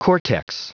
Prononciation du mot cortex en anglais (fichier audio)
Prononciation du mot : cortex